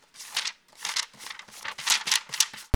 TURN PAGE8-S.WAV